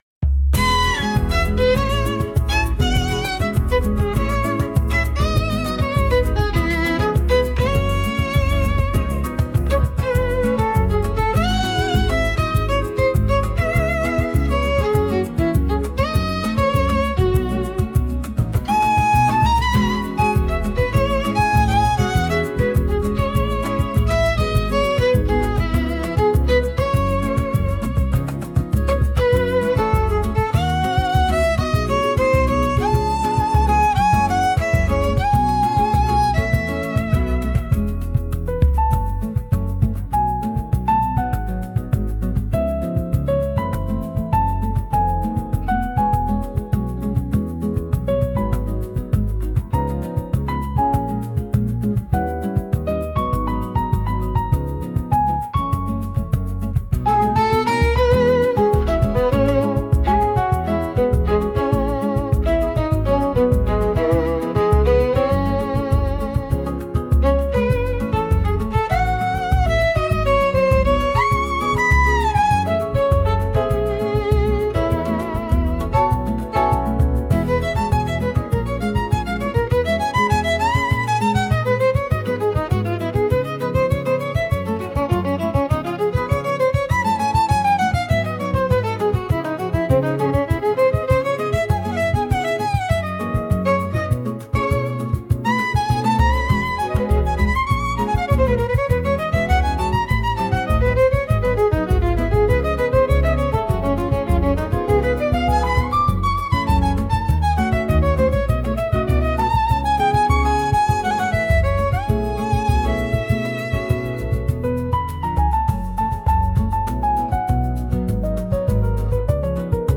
música, arranjo e voz: IA) instrumental 3